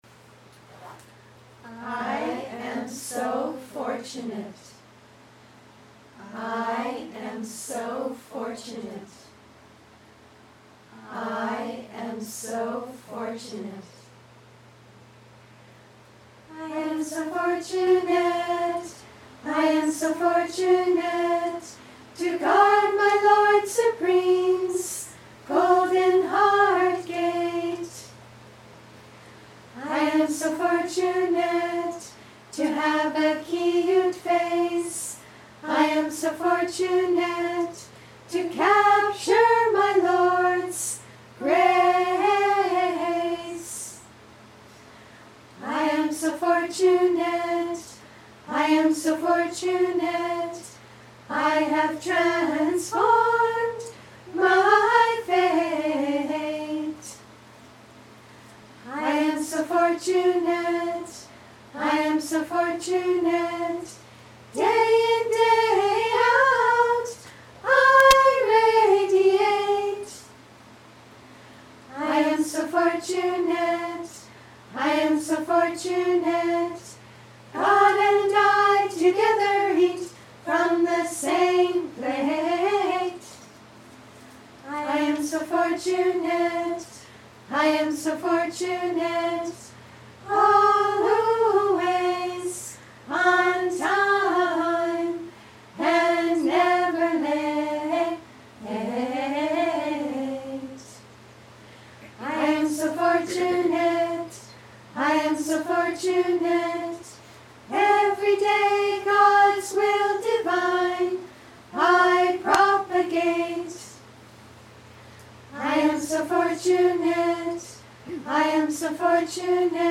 POEMS (1), read by members of Enthusiasm-Awakeners, 2008